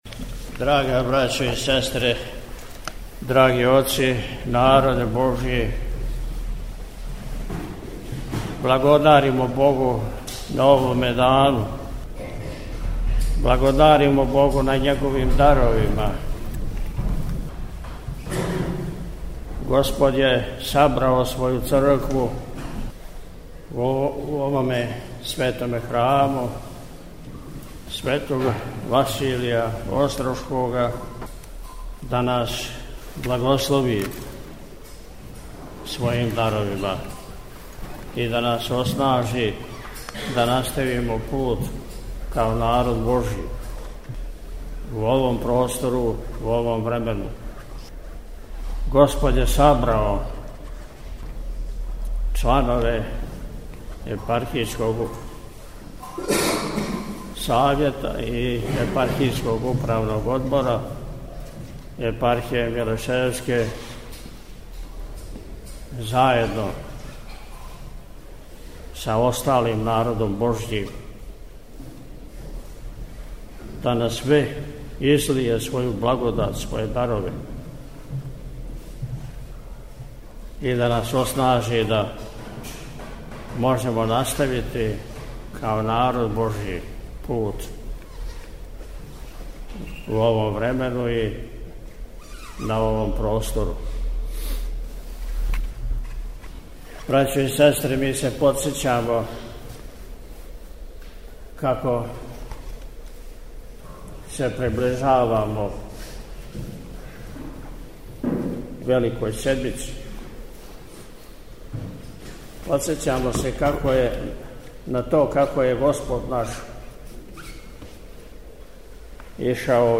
Subota-Beseda.mp3